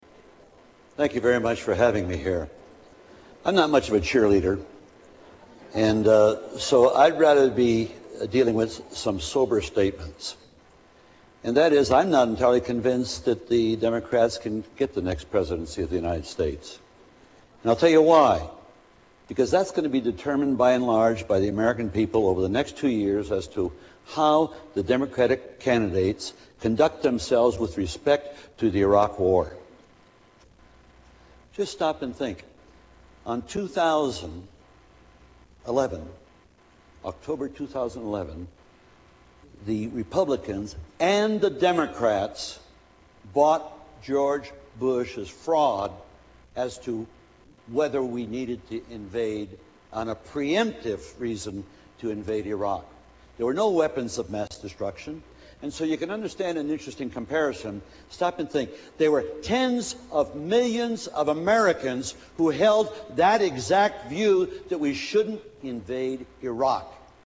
Tags: Political Mike Gravel Presidential Candidate Democratic Mike Gravel Speeches